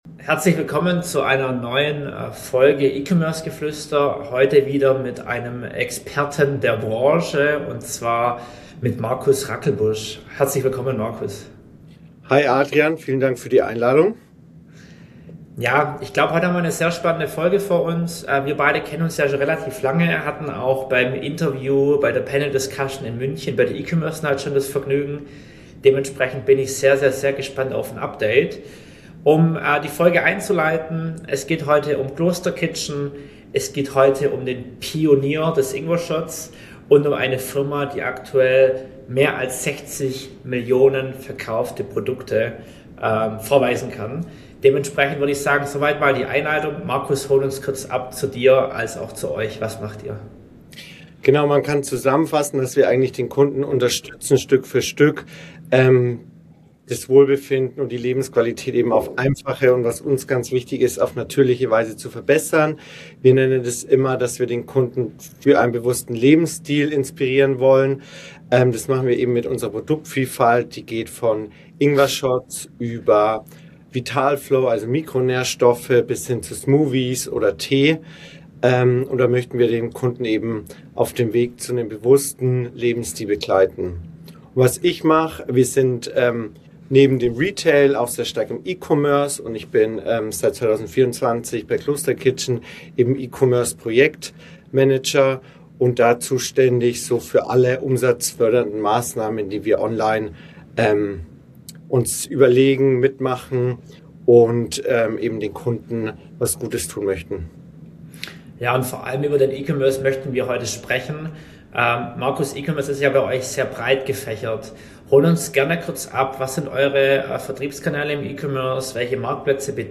Podcast: Marktplatz-Strategie 2026 – Amazon Neukundengewinnung bei Kloster Kitchen